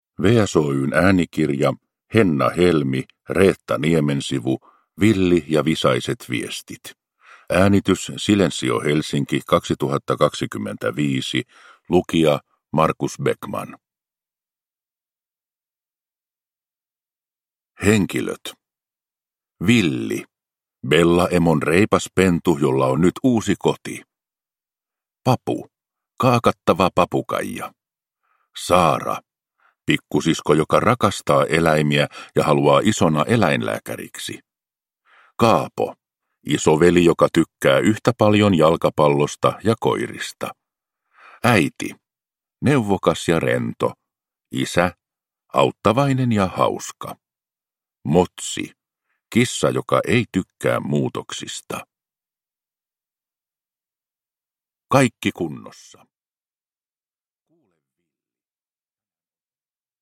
Villi ja visaiset viestit – Ljudbok